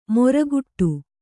♪ moraguṭṭu